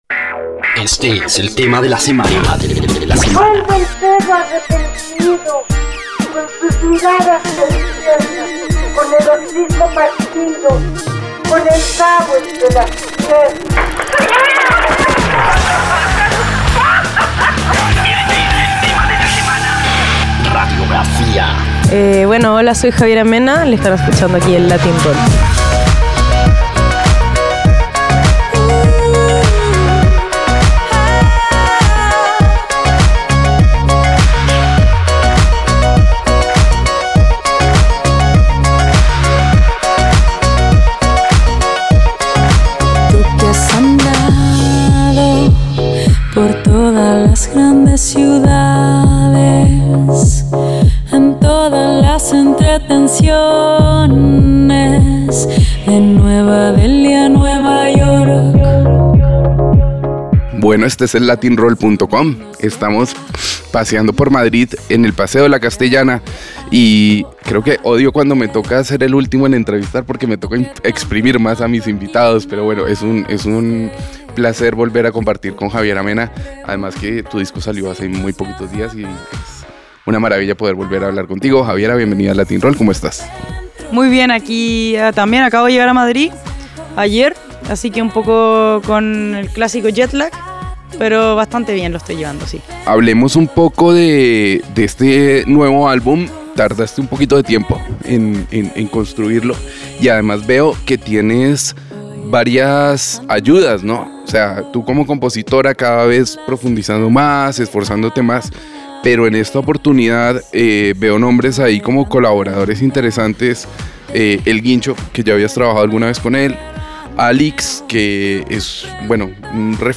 Facebook Pinterest Twitter Linkedin junio 27, 2018 Javiera Mena en Exclusiva Entrevista exclusiva con la chilena, quien nos contó los detalles de Espejo .